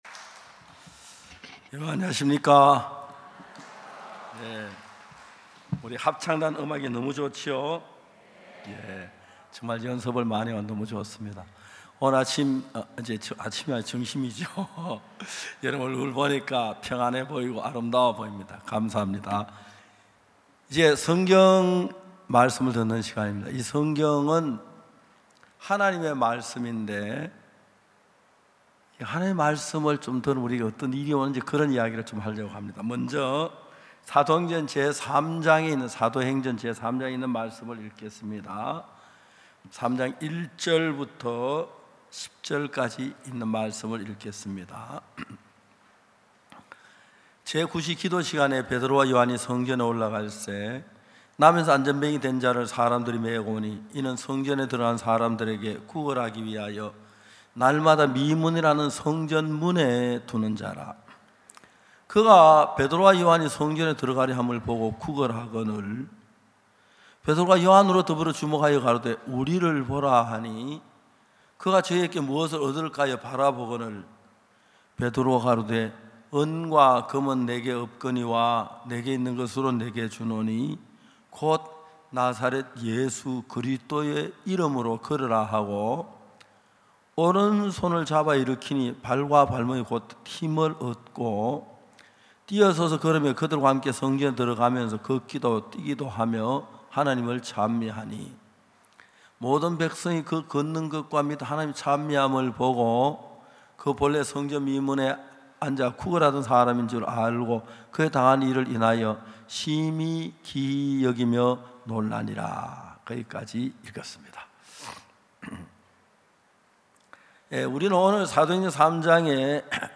2024 전반기 대전성경세미나